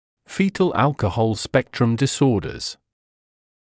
[‘fiːtl ‘ælkəhɔl ‘spektrəm dɪ’sɔːdəz][‘фиːтл ‘элкэхол ‘спэктрэм ди’соːдэз]фетальные нарушения алкогольного спектра